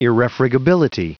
Prononciation du mot irrefragability en anglais (fichier audio)
irrefragability.wav